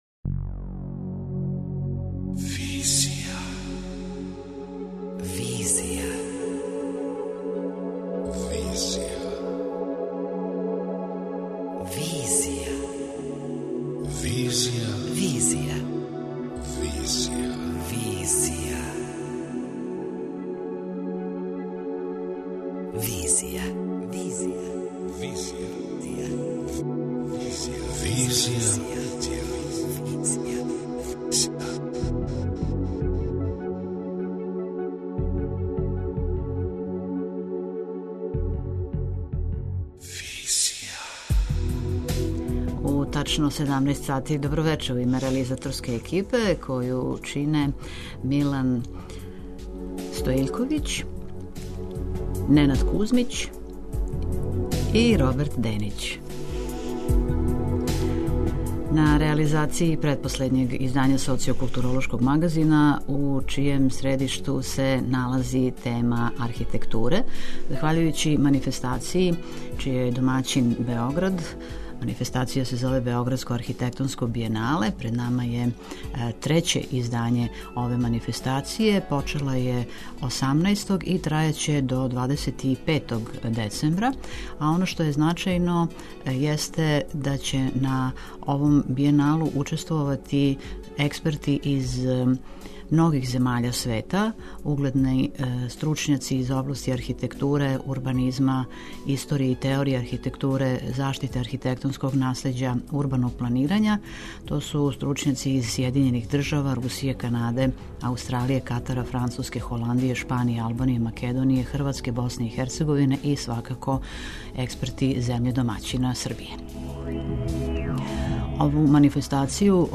преузми : 27.07 MB Визија Autor: Београд 202 Социо-културолошки магазин, који прати савремене друштвене феномене.